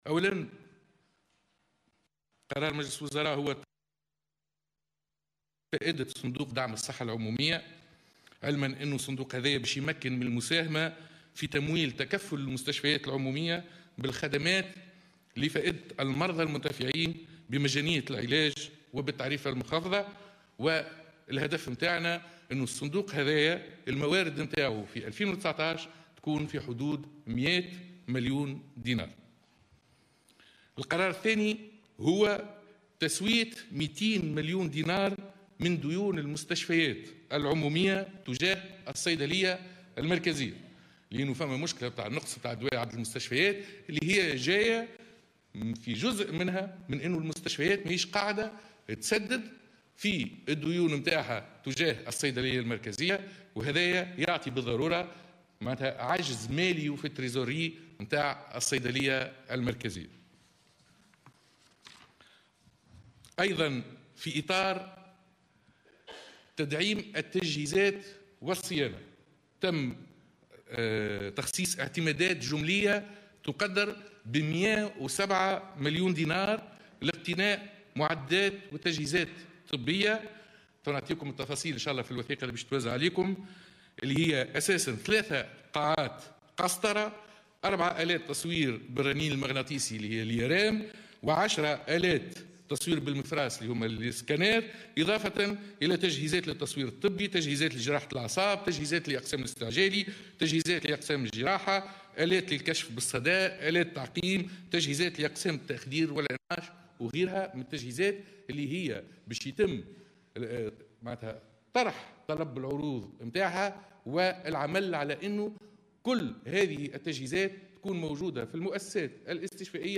الناطق الرسمي باسم الحكومة اياد الدهماني